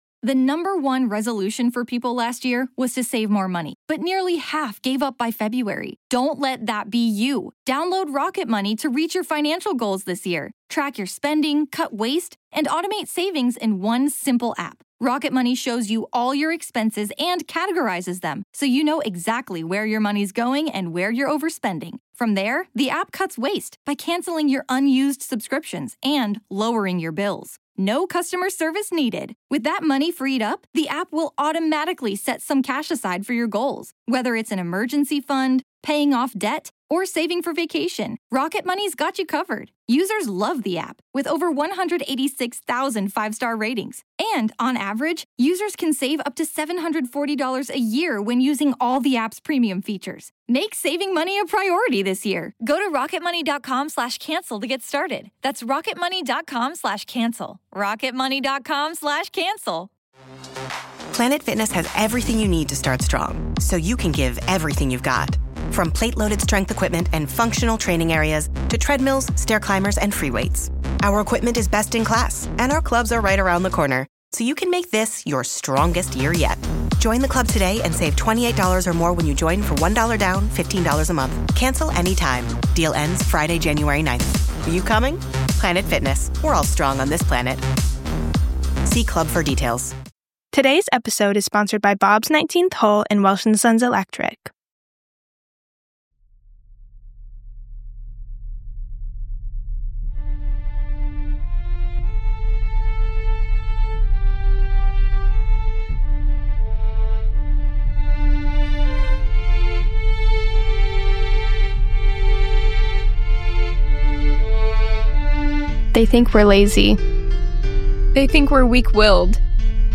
We contacted him and thankfully he agreed to an interview with us.